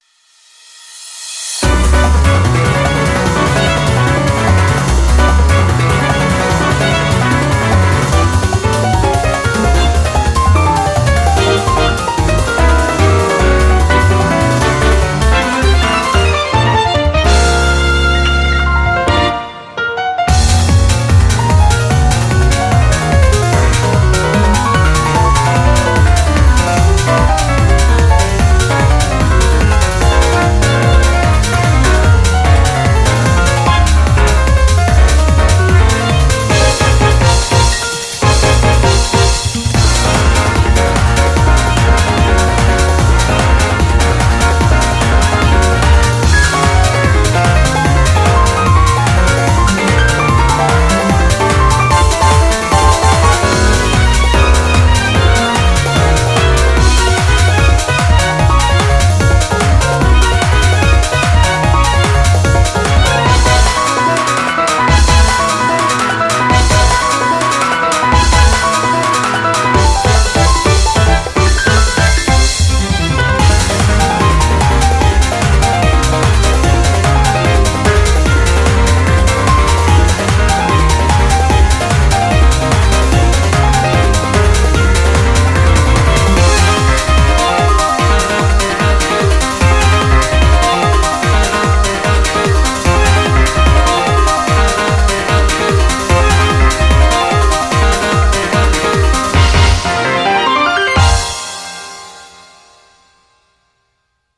BPM148
Audio QualityLine Out